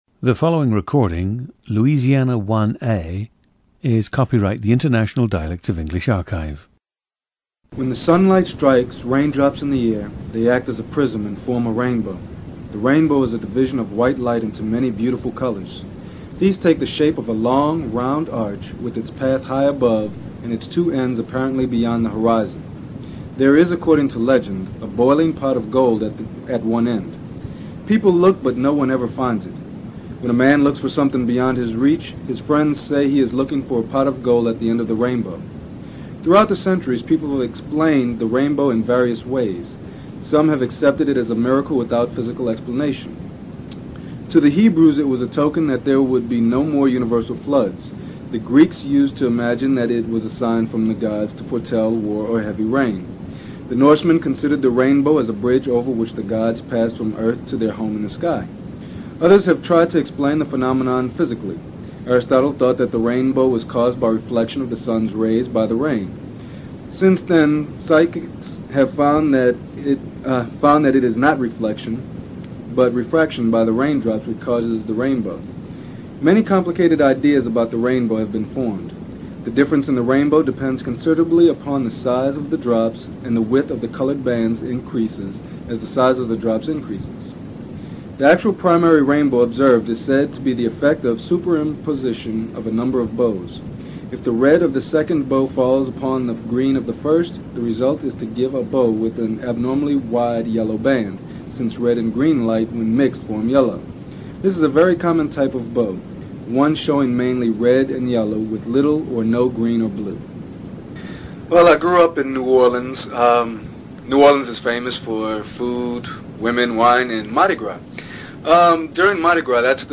PLACE OF BIRTH: New Orleans, Louisiana
GENDER: male
While much of his speech sounds like educated Southern Black, African-American listeners will probably detect some Spanish rhythms.
The recordings average four minutes in length and feature both the reading of one of two standard passages, and some unscripted speech.